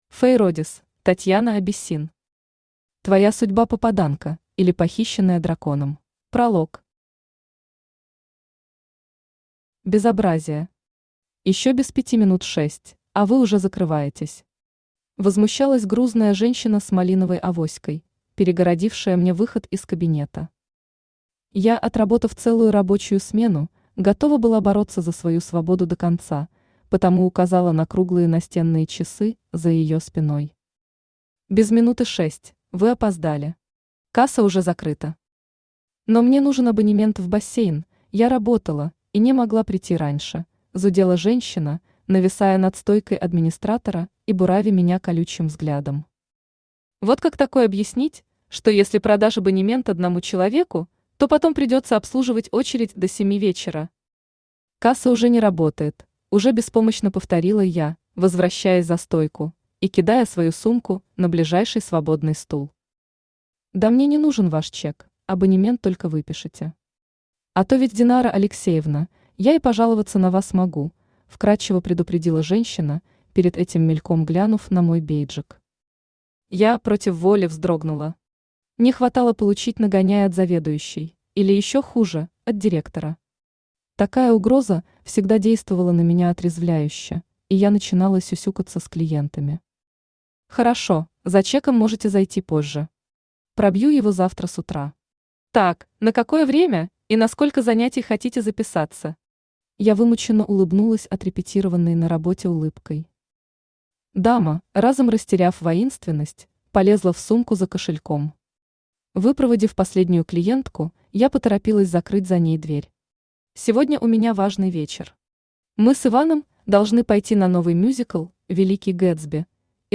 Aудиокнига Твоя судьба-попаданка, или Похищенная драконом Автор Татьяна Абиссин Читает аудиокнигу Авточтец ЛитРес.